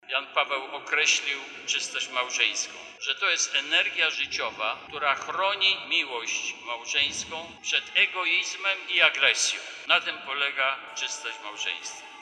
Bp senior diecezji warszawsko-praski przewodniczył mszy św. w parafii Najczystszego Serca Maryi na Placu Szembeka podczas dorocznego Diecezjalnego Dnia Wspólnoty Domowego Kościoła – gałęzie rodzinnej Ruchu Światło-Życie.
W homilii przypomniał o znaczeniu sakramentu małżeństwa podkreślając, że więź łącząca dwóje ludzi powinna być czysta.